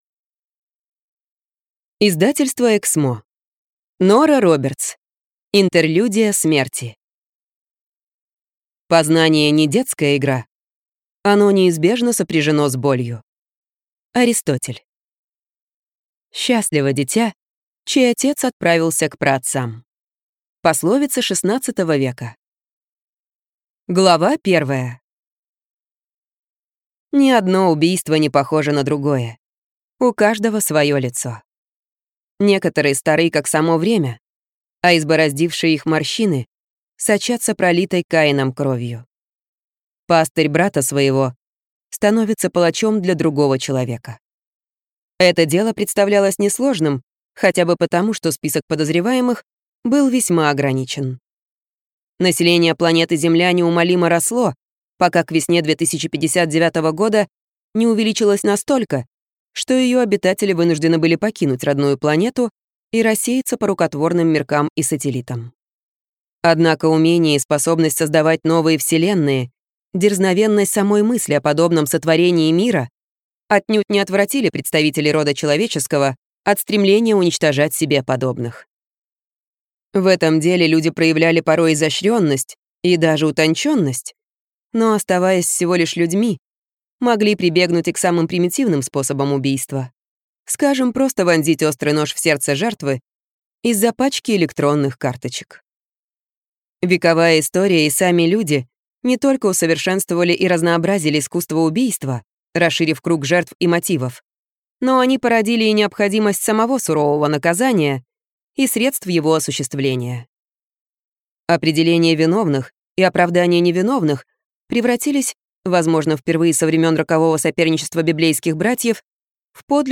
Аудиокнига Интерлюдия смерти | Библиотека аудиокниг